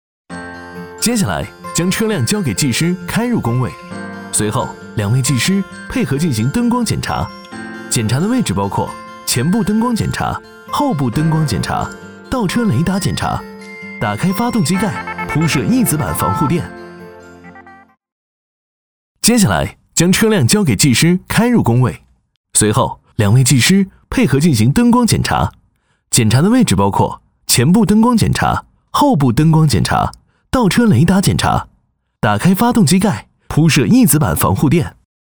年轻时尚 产品解说
年轻活力气势、清新感性男中音。